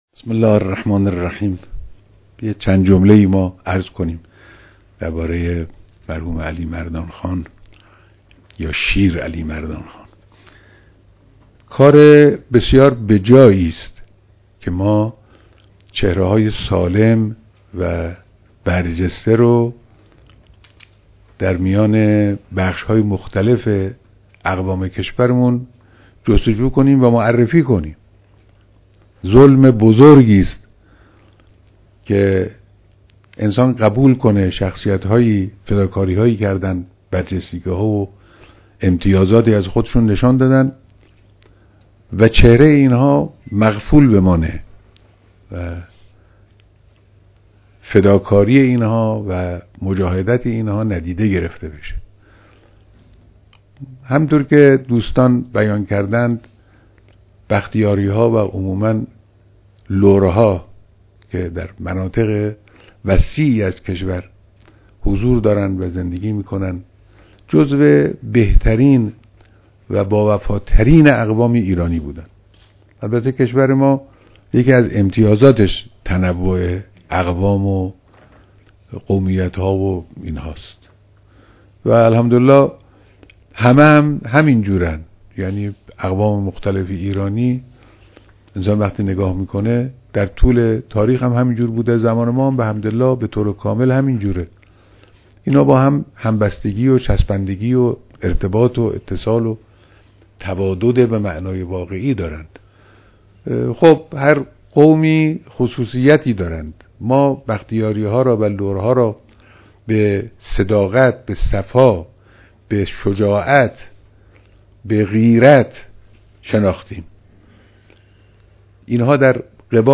بیانات در دیدار اعضای ستاد نکوداشت شیرعلی مردان خان بختیاری